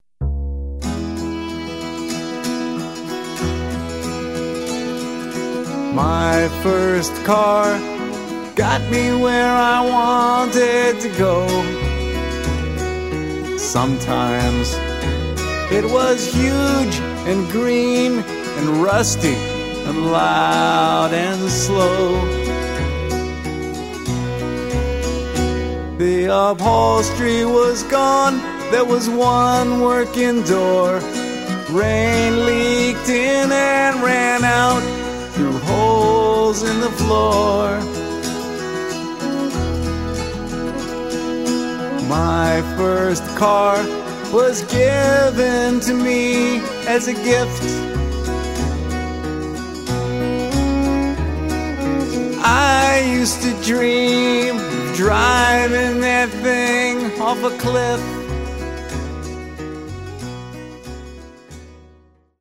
acoustic trio
fiddler